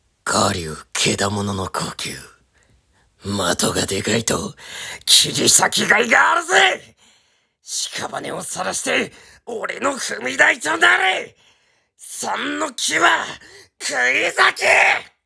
嘴平伊之助 声真似